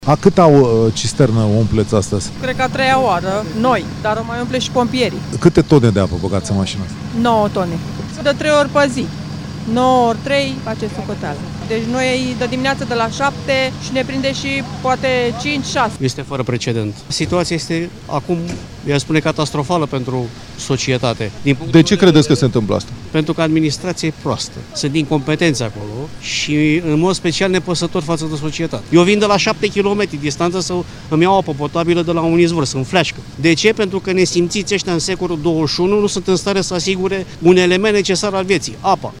Vin de la șapte kilometri distanță să îmi iau apă potabilă”, a spus un localnic